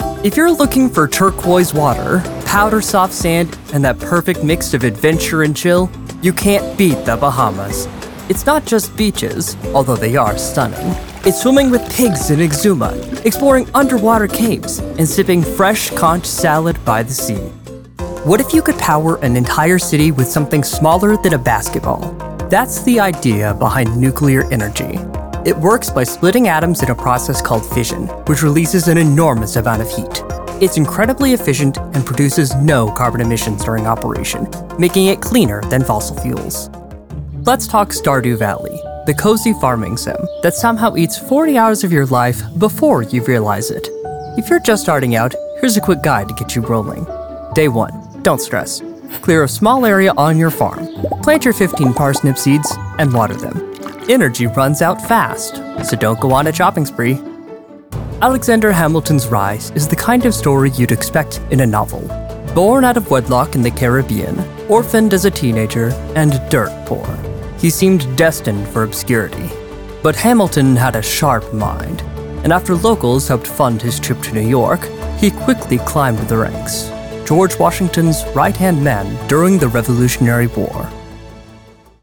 Narration Demo
Southern and some Scottish, English, and Irish
Young Adult